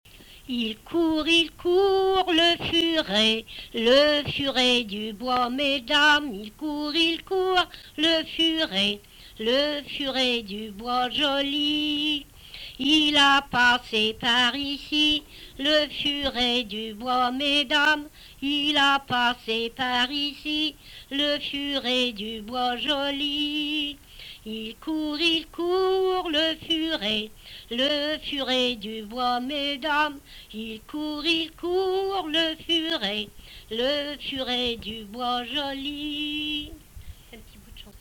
Chanson
Emplacement Miquelon